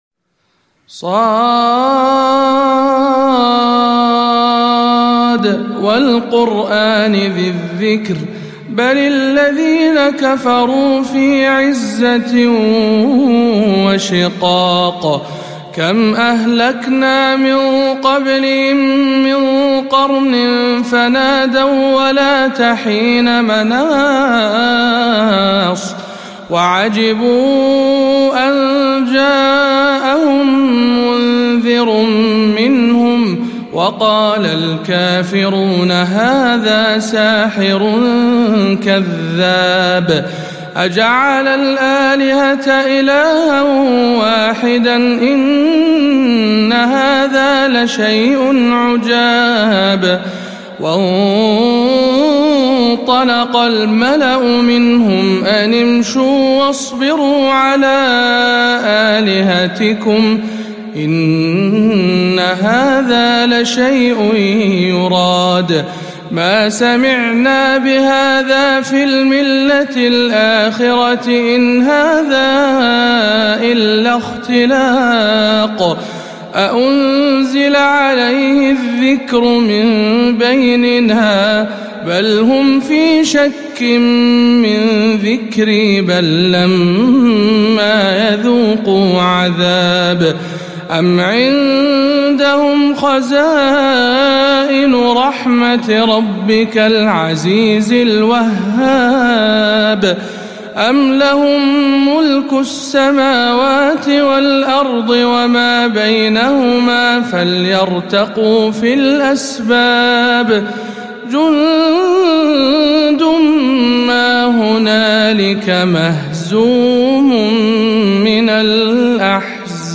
06. سورة ص بجامع الحمدان بدولة الكويت - رمضان 1437 هـ